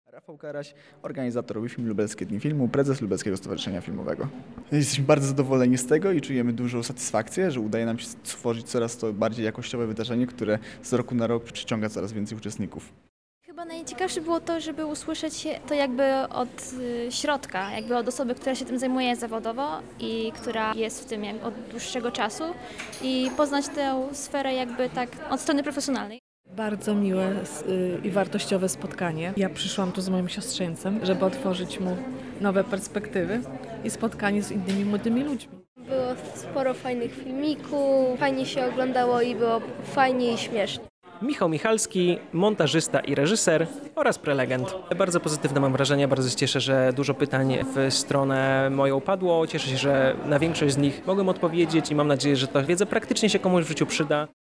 WeFilm – Lubelskie Dni Filmu – relacja z wydarzenia
Na miejscy był nasz reporter.